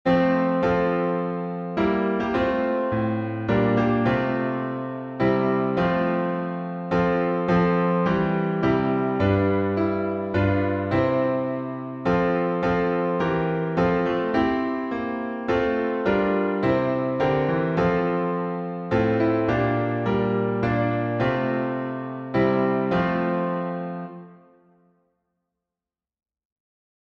#5035: The Lord's My Shepherd, I'll Not Want — alternate chording | Mobile Hymns
Key signature: F major (1 flat) Time signature: 3/4